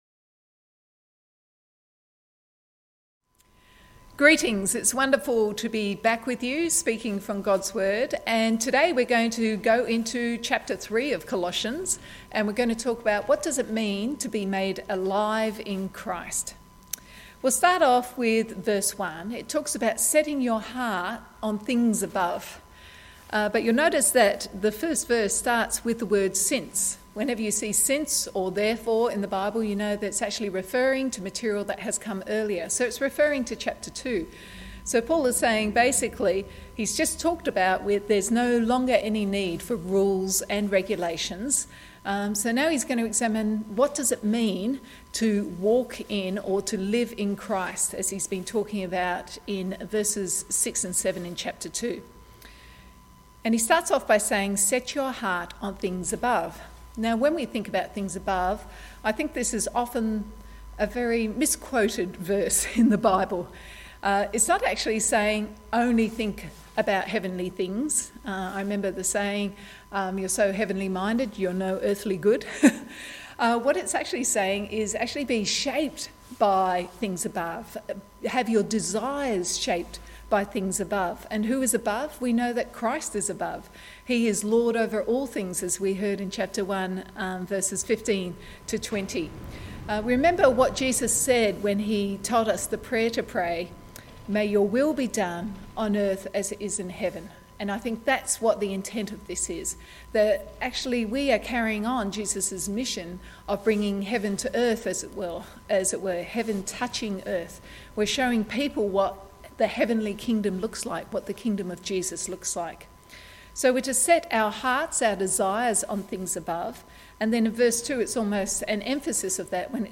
Bible Text: Colossians 3:1-17 | Preacher